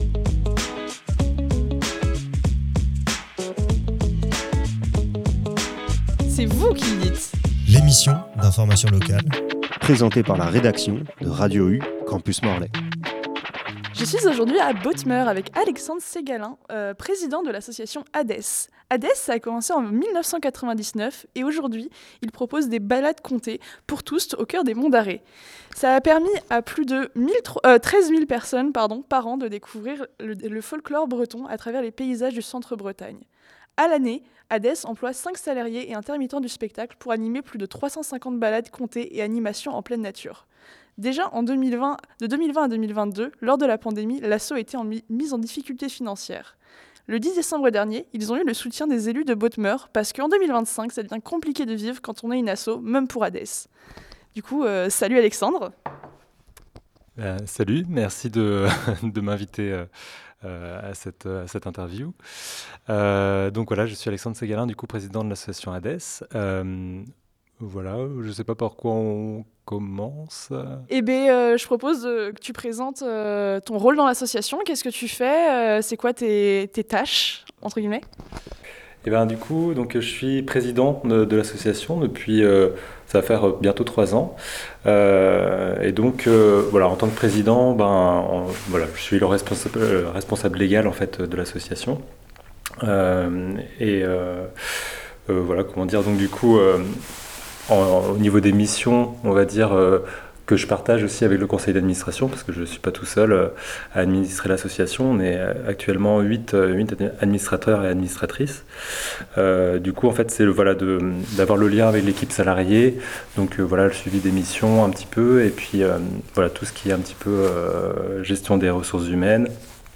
itw_addes_monte.mp3